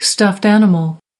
8. stuffed animal (n) /stʌft ˈænəməl/ thú nhồi bông